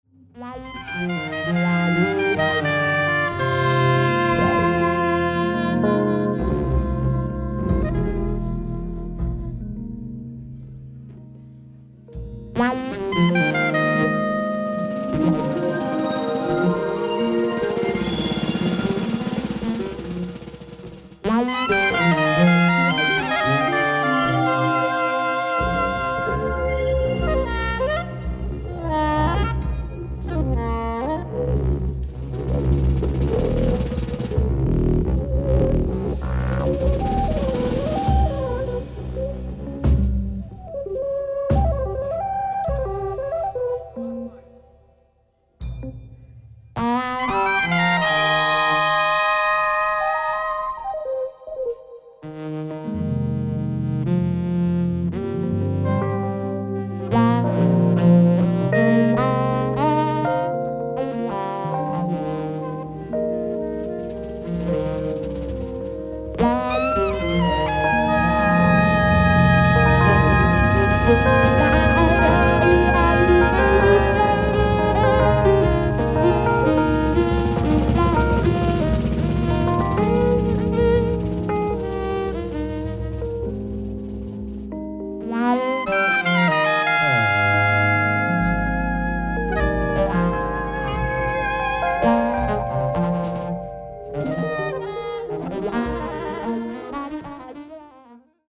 ライブ・アット・キアッソ、スイス
※試聴用に実際より音質を落としています。